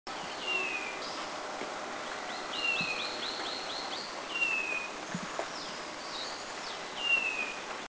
Taiwan Barwing Actinodura morrisoniana Country endemic
B2A_TaiwanBarwingYushan410_SDW.mp3